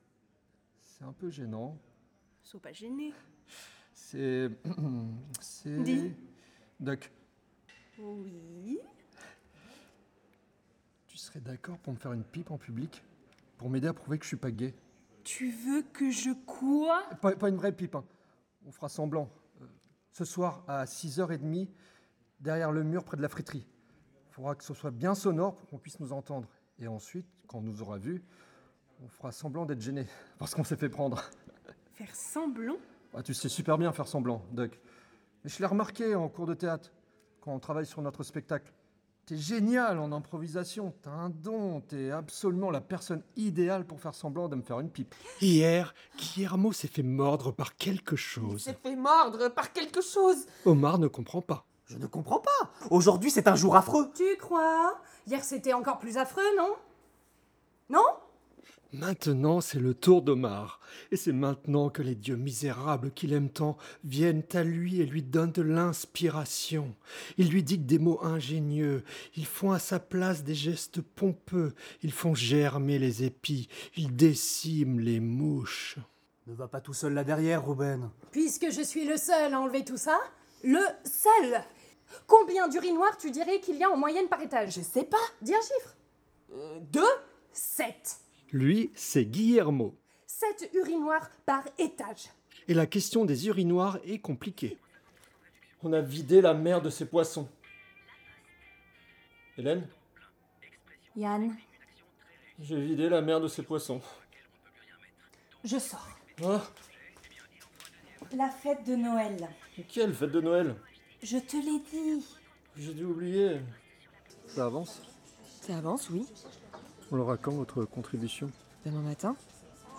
Voix off
30 - 40 ans - Baryton-basse